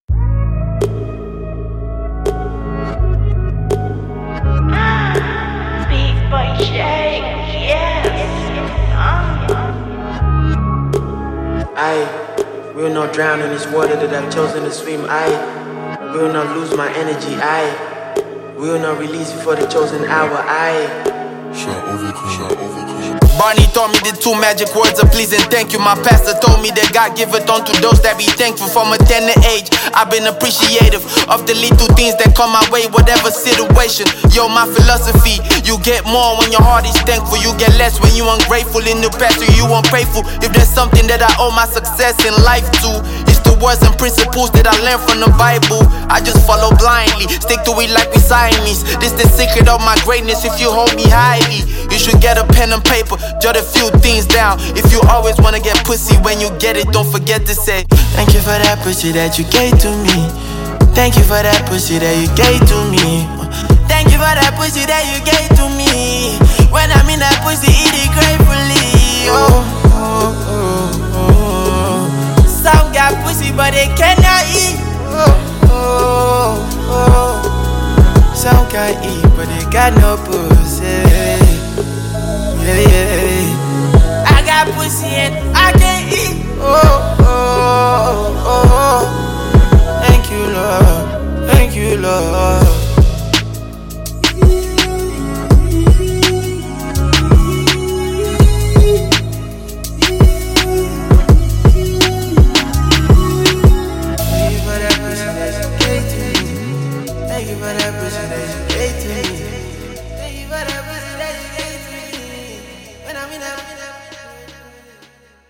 rapper and singer